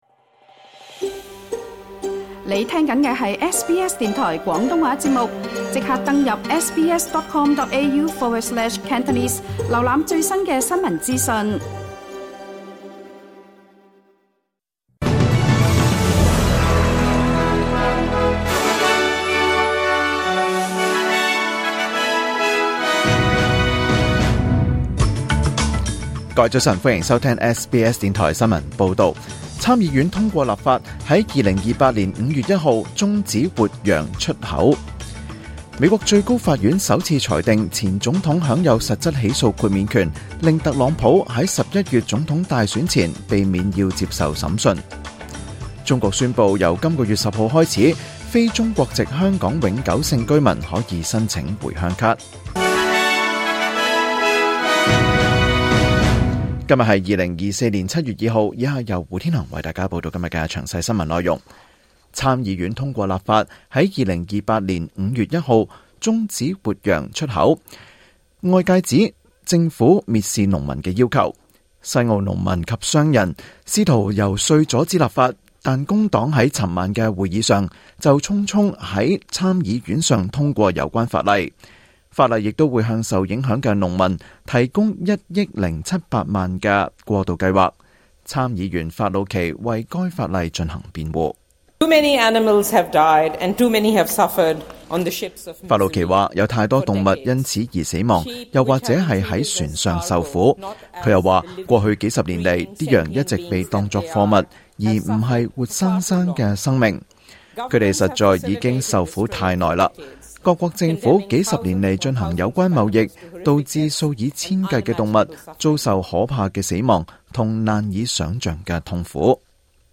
2024年7月2日SBS廣東話節目詳盡早晨新聞報道。